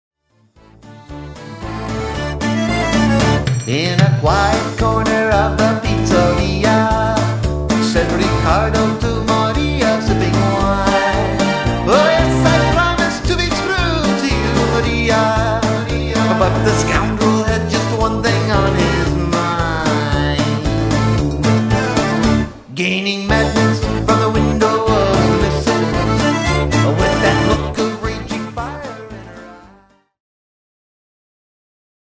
A WIDE VARIETY OF ROCK INCLUDING,